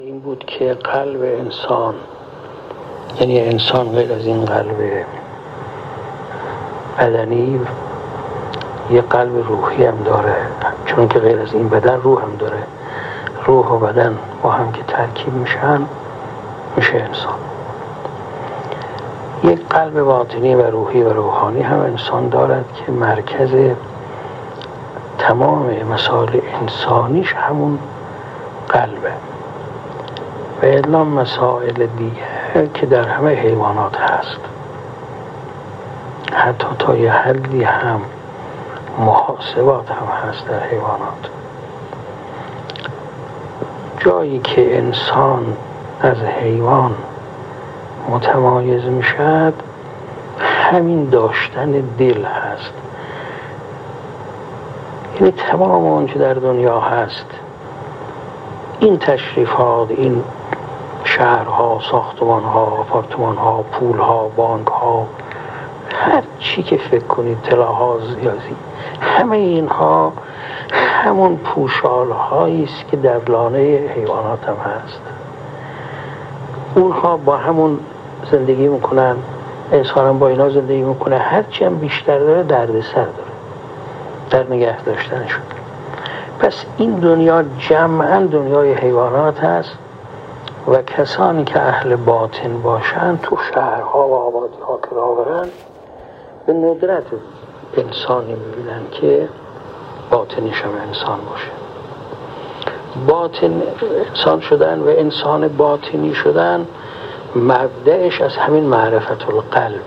مرحوم علامه محمدرضا حکیمی، فیلسوف و دانشمند برجسته در یکی از جلسات اخلاق خود به بیان وجه تمایز انسان با سایر موجودات اشاره می کند و می‌گوید: قلب روحی انسان مرکز تمام مسائل انسانی اوست.